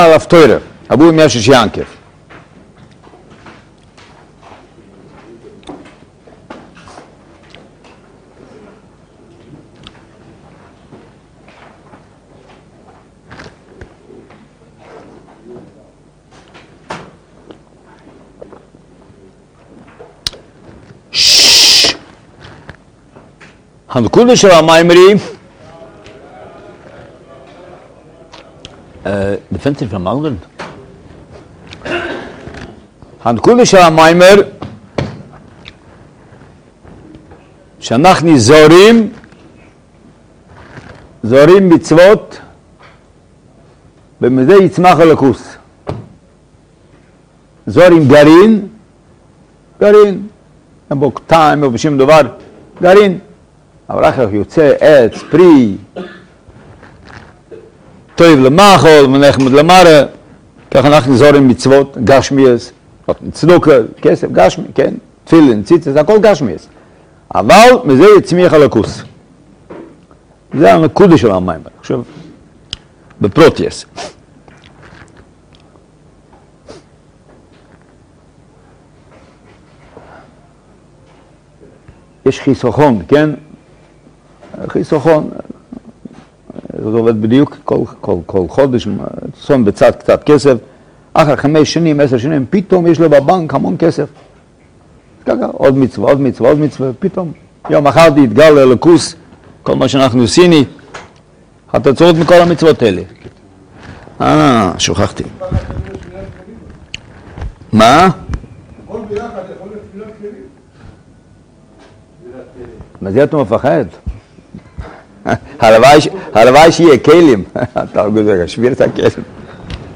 שיעור יומי